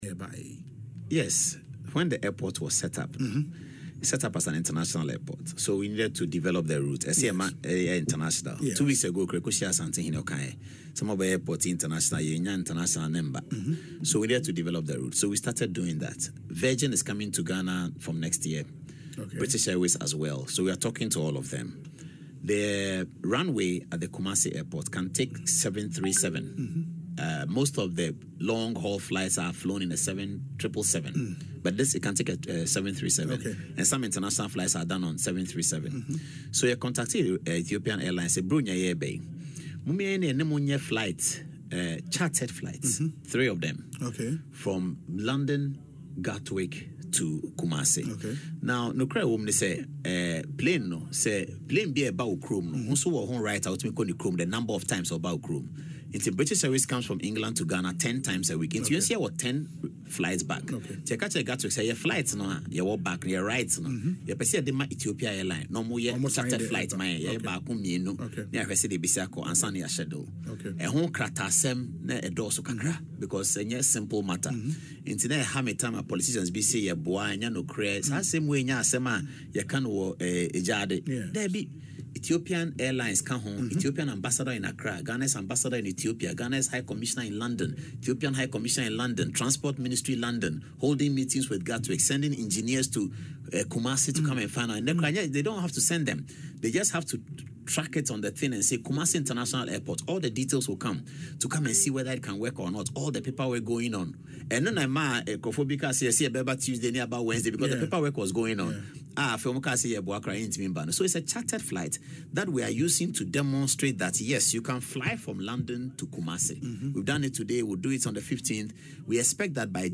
Mr Adom-Otchere disclosed this on Asempa FM Ekosii Sen programme Friday following the arrival of the Ethiopian Airlines flight ETH8719 in Kumasi.